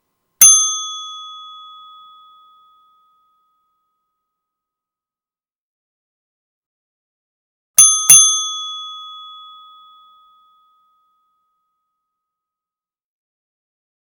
Little bell
bell cartoon chime ding ping ring ringing shine sound effect free sound royalty free Movies & TV